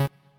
left-synth_chord30.ogg